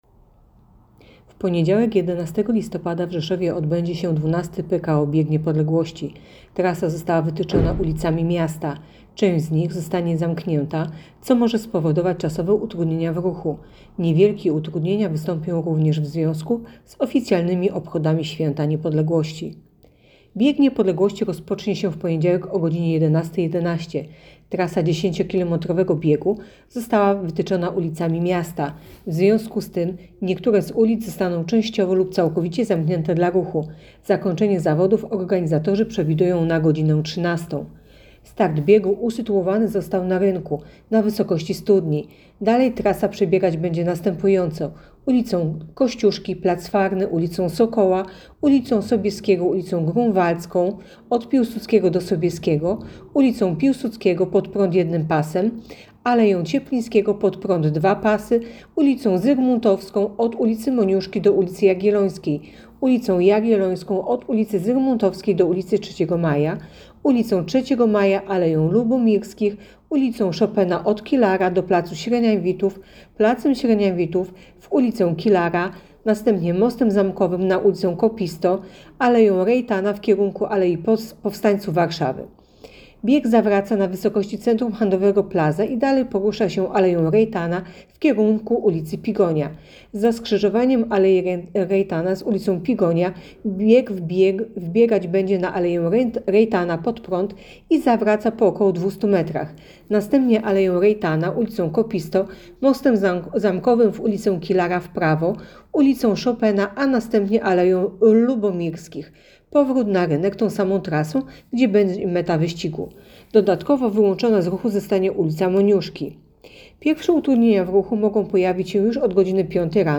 Mówi podkomisarz